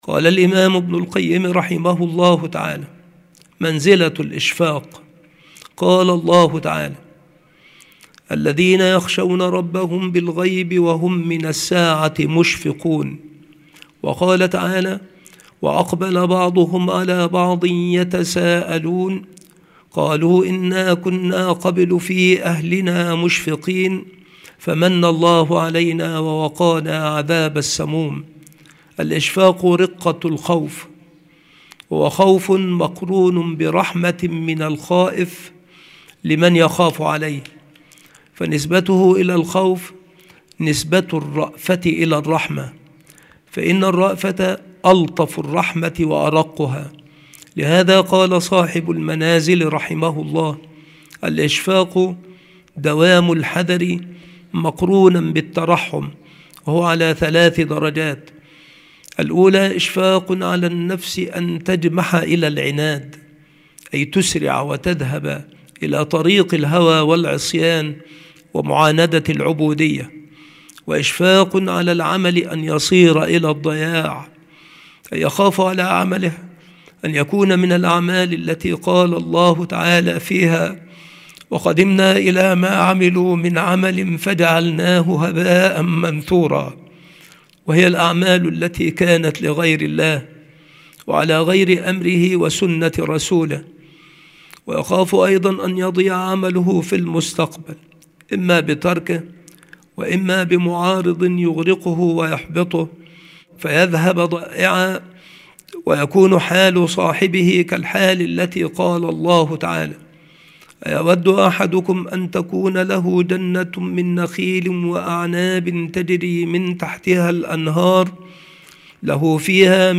• مكان إلقاء هذه المحاضرة : المكتبة - سبك الأحد - أشمون - محافظة المنوفية - مصر